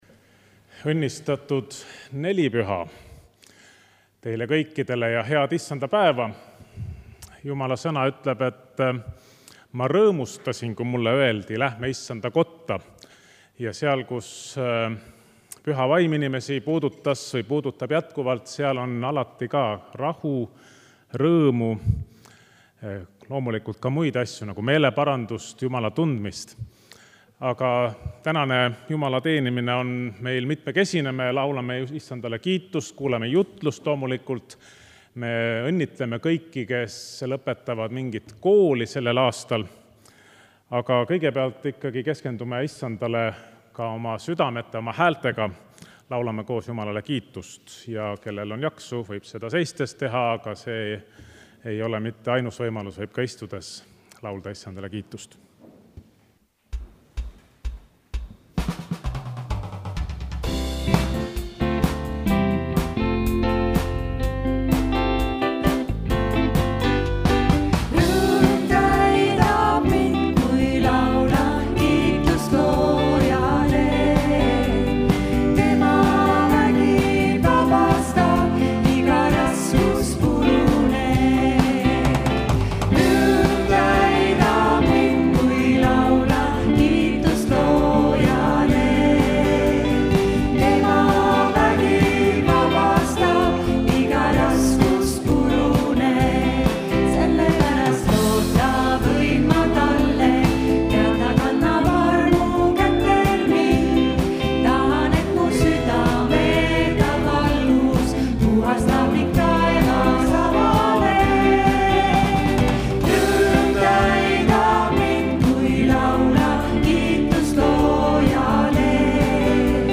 Pühakirja lugemine: Ap 2:1-13